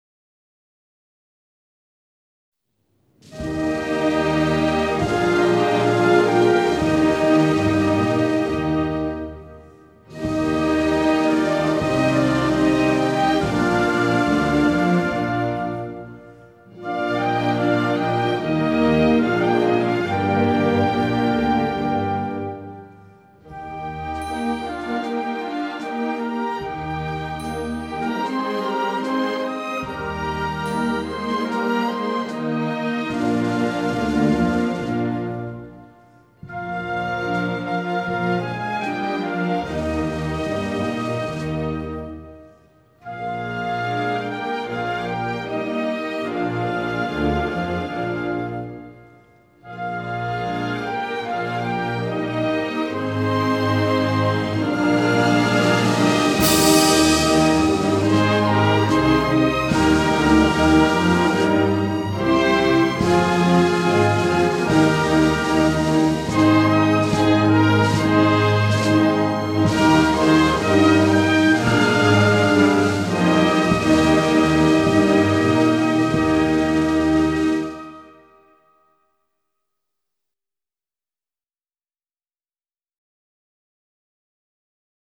เพลงสรรเสริญพระบารมี (บรรเลง)